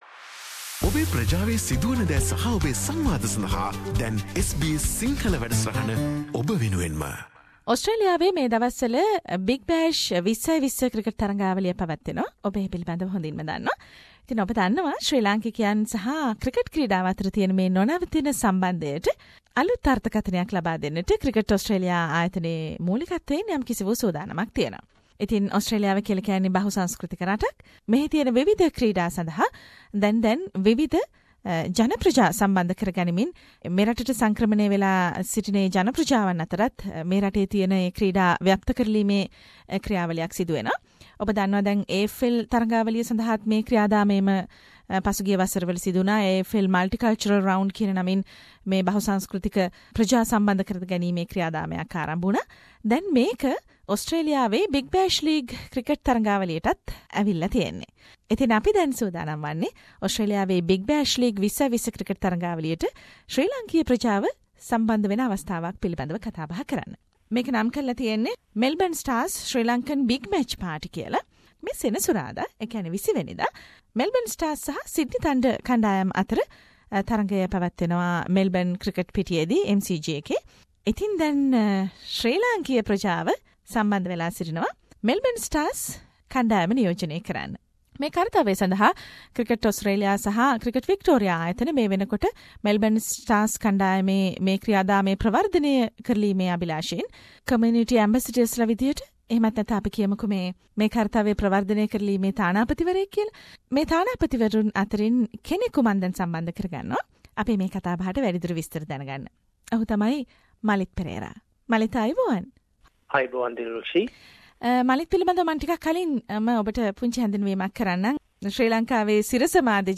වැඩි විස්තර මෙම සාකච්චාවෙන්....